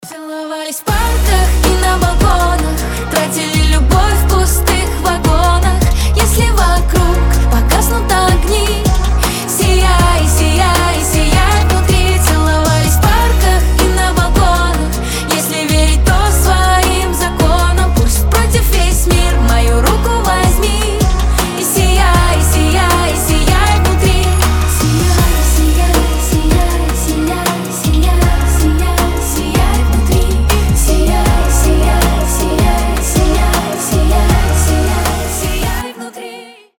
женский голос